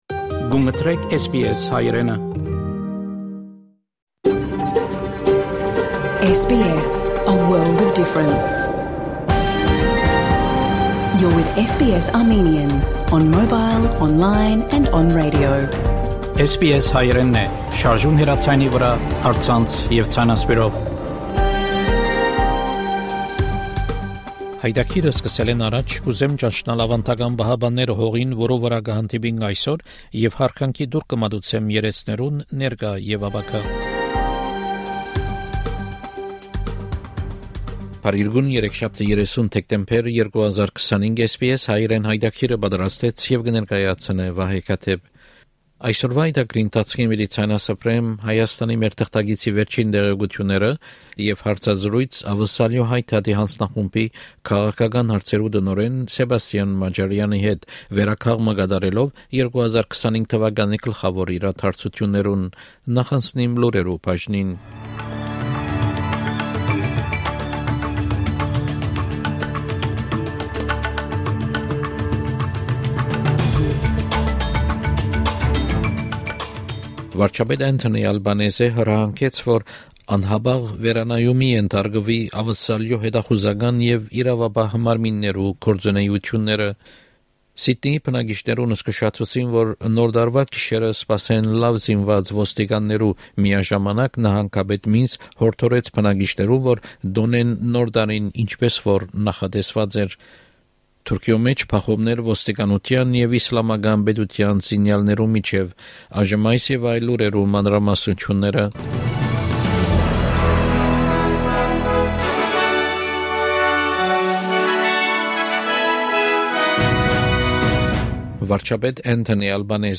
SBS Armenian news bulletin from 30 December 2025 program.